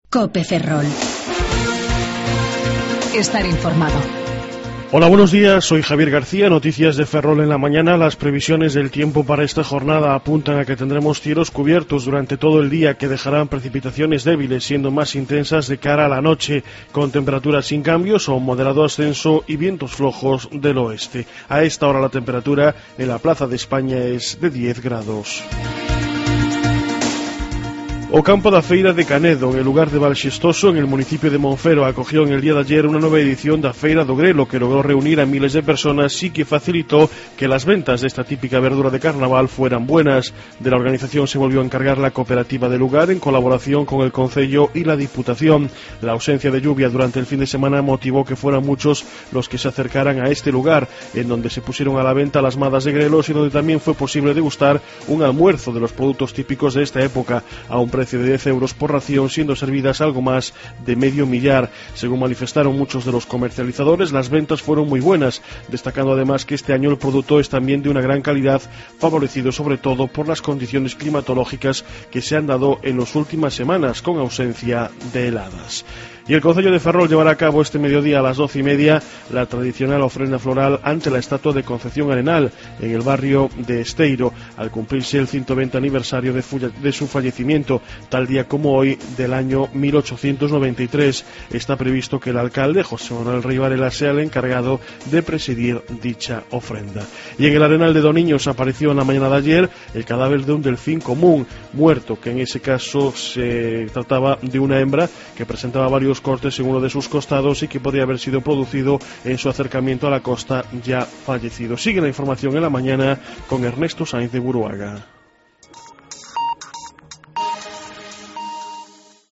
07:28 Informativo La Mañana